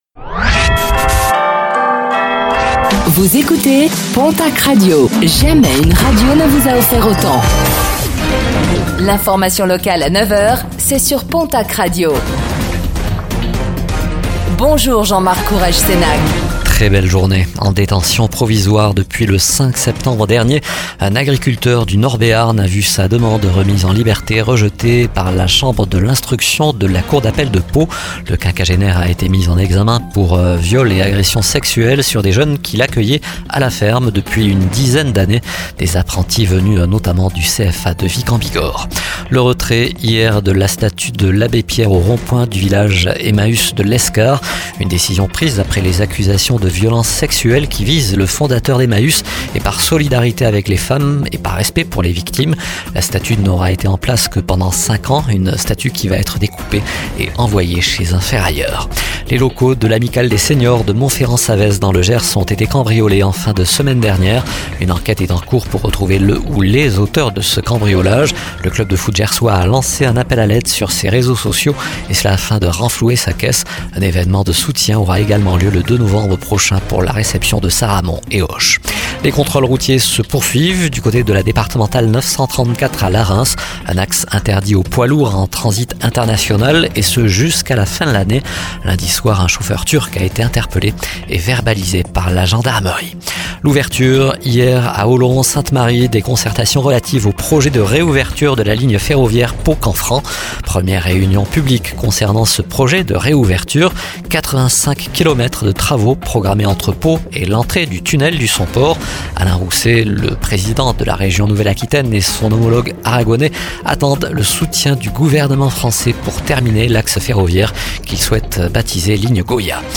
Infos | Mercredi 25 septembre 2024